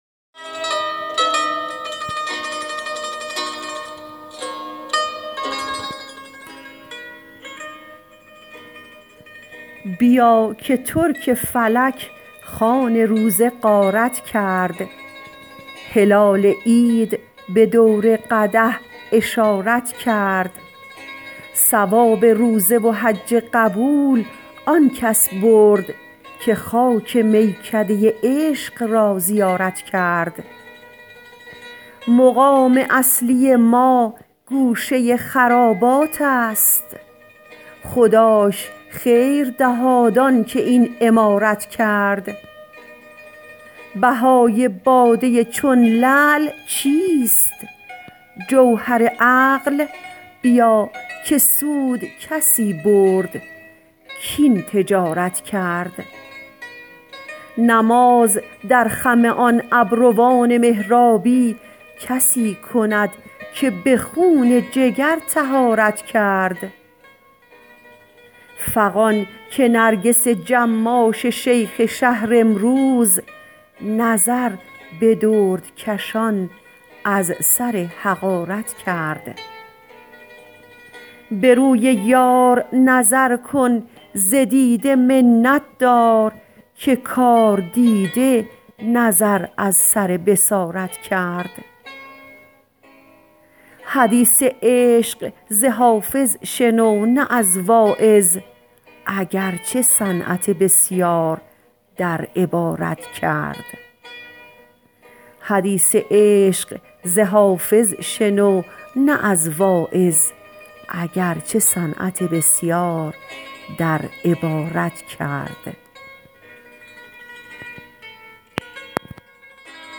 حافظ غزلیات غزل شمارهٔ ۱۳۱ به خوانش